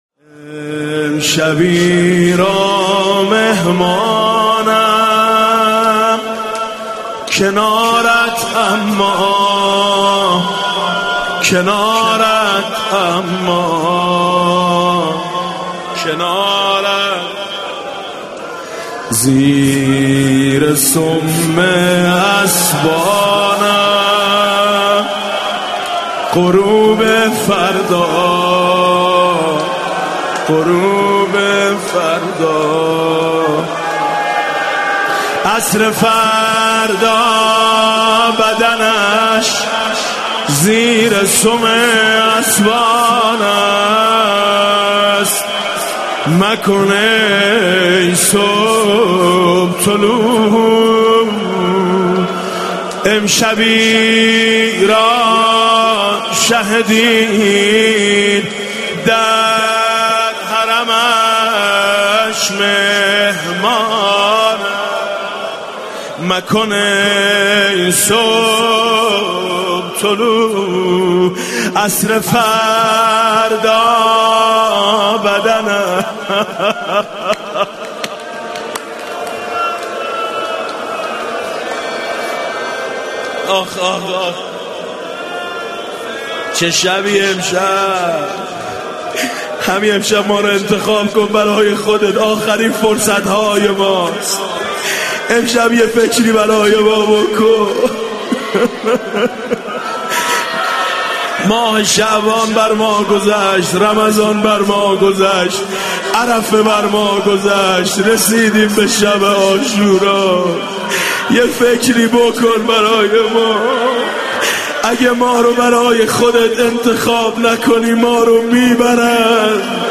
اناشيد